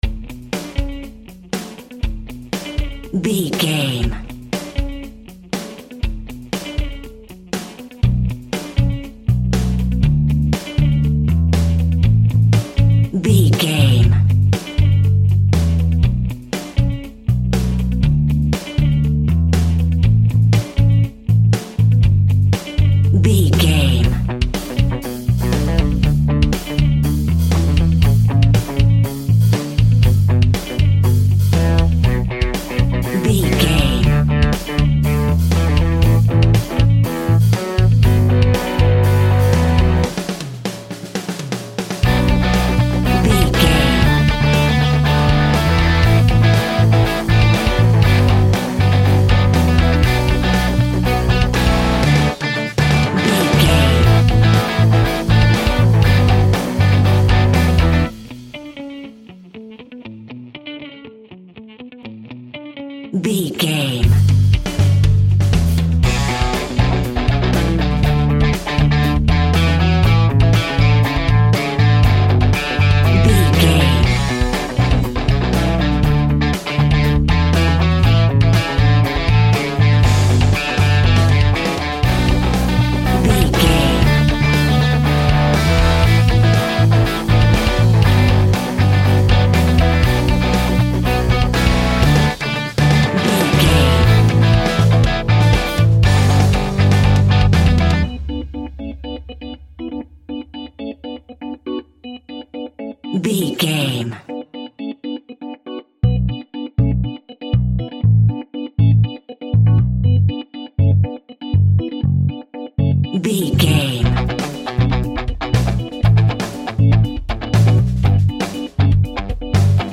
Rock Grunge Music.
Epic / Action
Fast paced
Aeolian/Minor
hard rock
heavy rock
distortion
rock instrumentals
rock guitars
Rock Bass
Rock Drums
heavy drums
distorted guitars
hammond organ